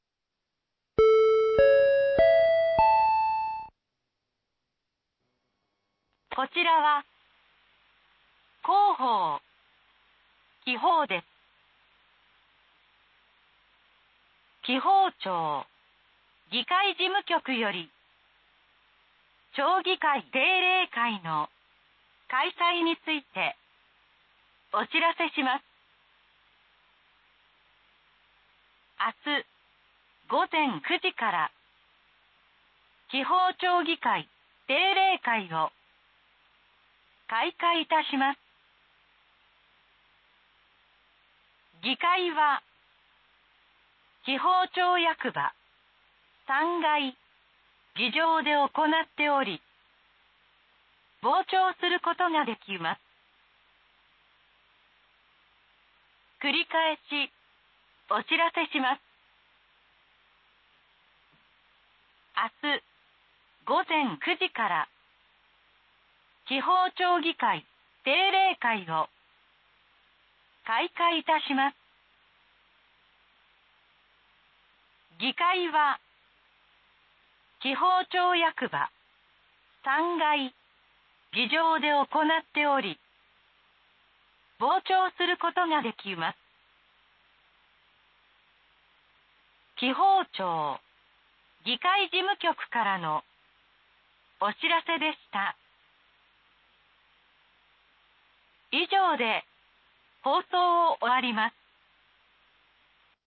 防災無線放送内容 | 紀宝町防災メール配信サービス | ページ 2